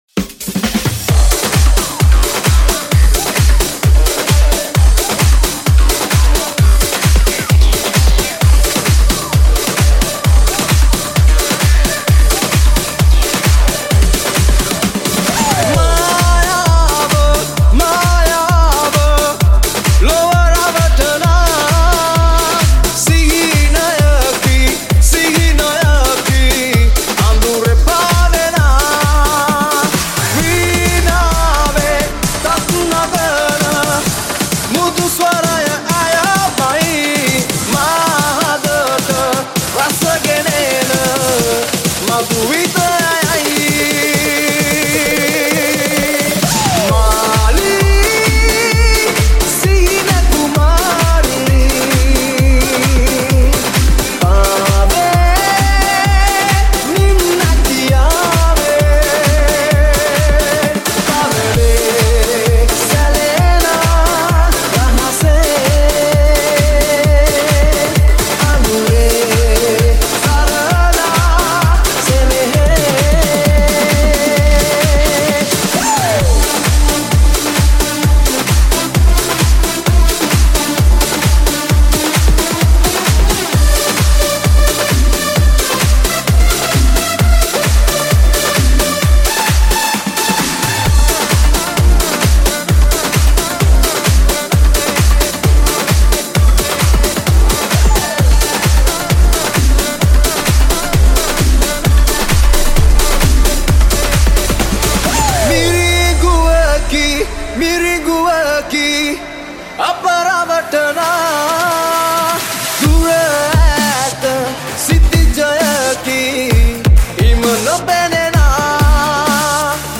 House Remix